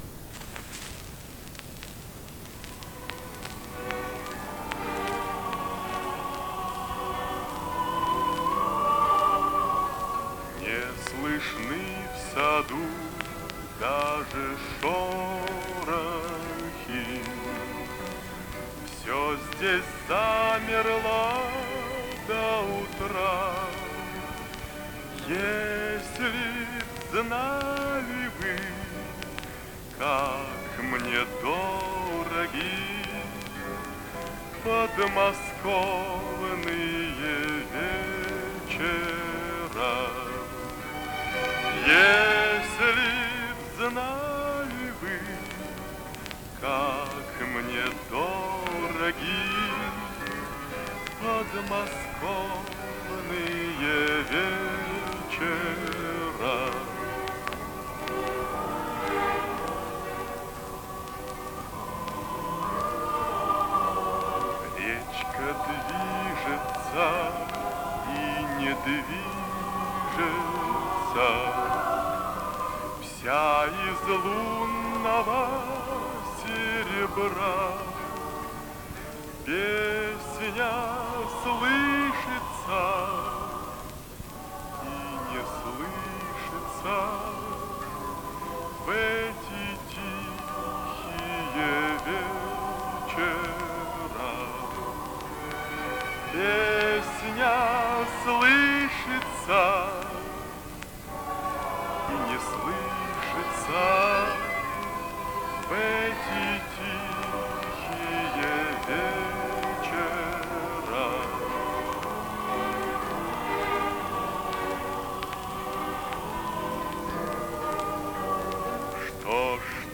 Die Musikstücke habe ich jetzt digitalisiert und aufgepeppt.